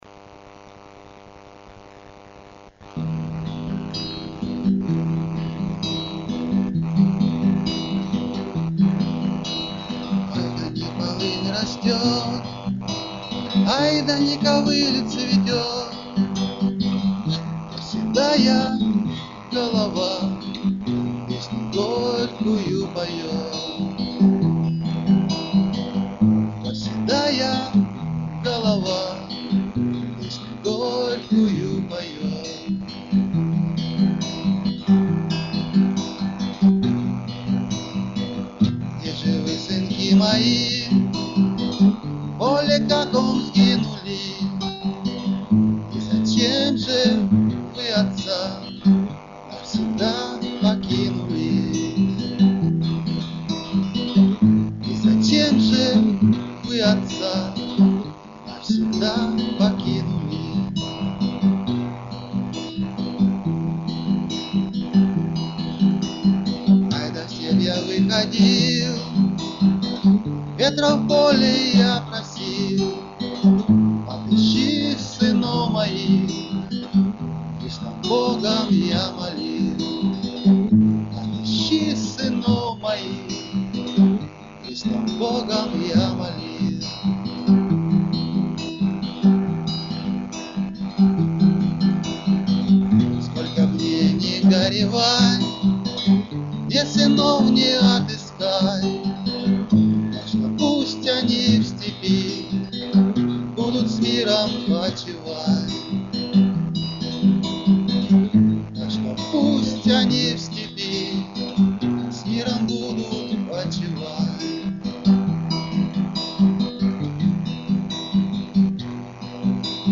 Рубрика: Поезія, Авторська пісня
Замечательный голос... girl_sigh слушал бы и слушал tender clap
12 Красива, щемна пісня.